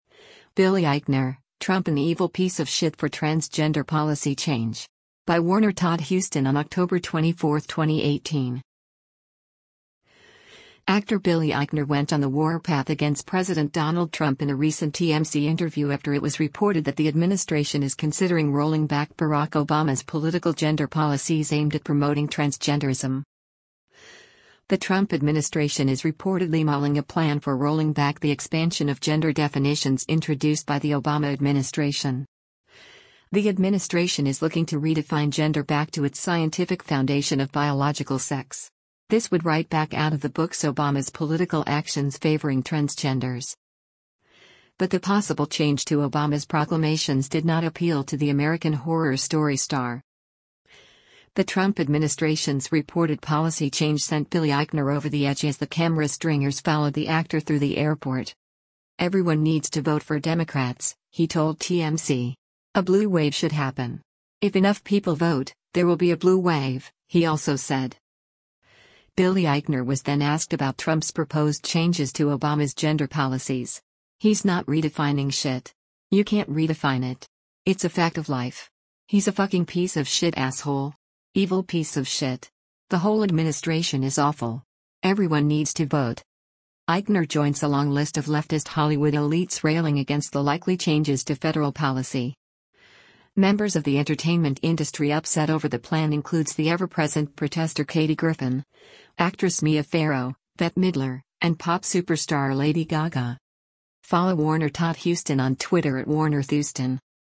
Actor Billy Eichner went on the warpath against President Donald Trump in a recent TMZ interview after it was reported that the administration is considering rolling back Barack Obama’s political gender policies aimed at promoting transgenderism.
The Trump administration’s reported policy change sent Billy Eichner over the edge as the camera stringers followed the actor through the airport.